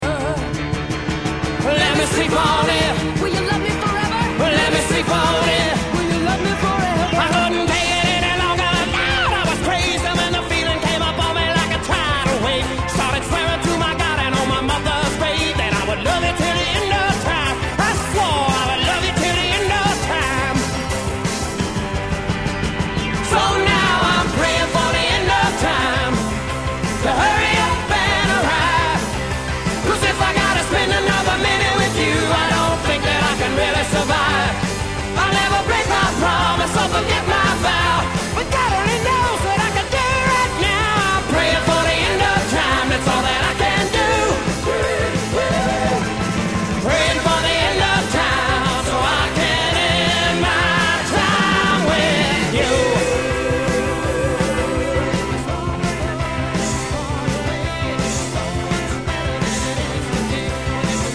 My Favorite Songs with Notable Bass Guitar Performances